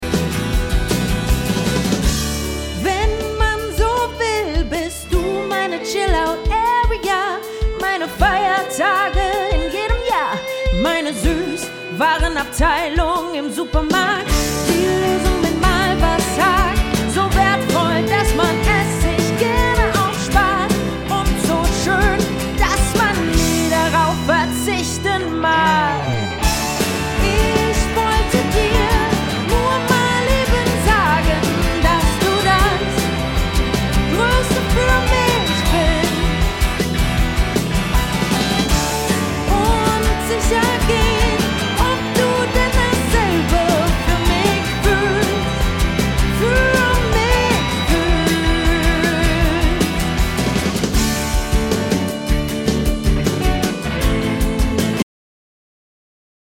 Soul, Rock und Pop
LIVE COVER